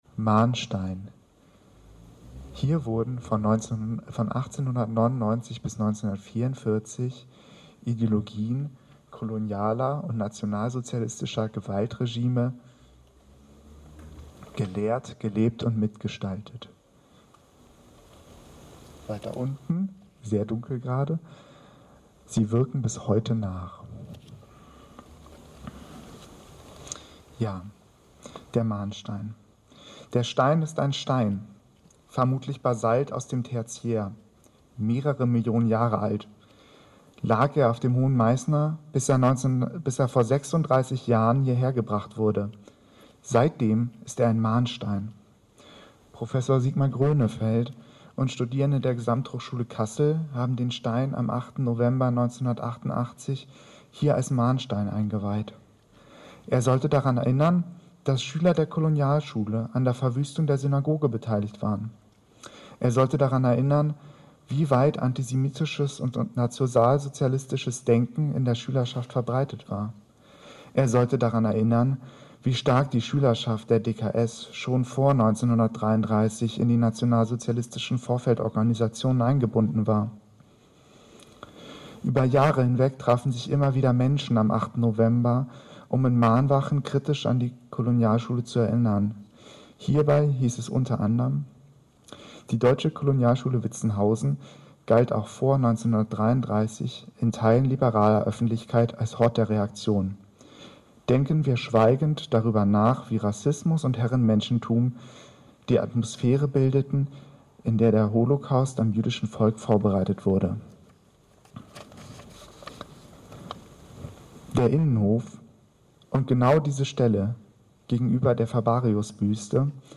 Redebeiträge: